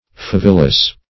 Search Result for " favillous" : The Collaborative International Dictionary of English v.0.48: favillous \fa*vil"lous\, a. [L. favilla sparkling or glowing ashes.] Of or pertaining to ashes.